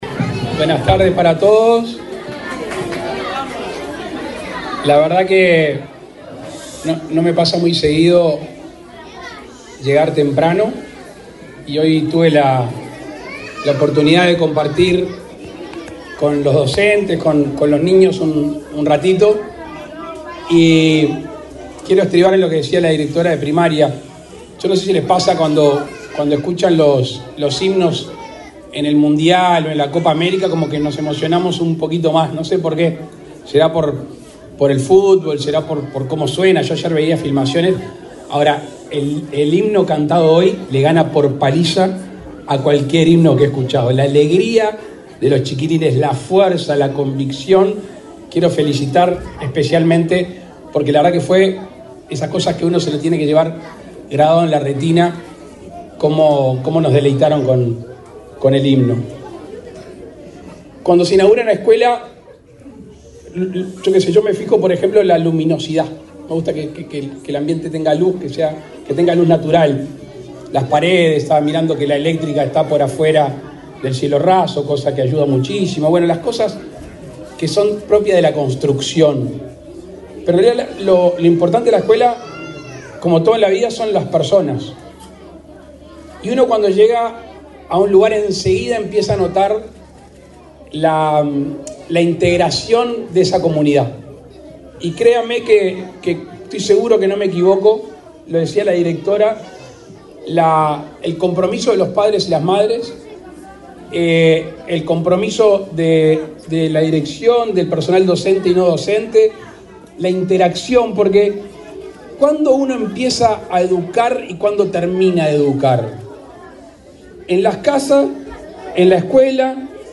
Palabras del presidente Luis Lacalle Pou
El presidente de la República, Luis Lacalle Pou, encabezó, este viernes 28 en Montevideo, el acto de inauguración de una escuela de tiempo completo en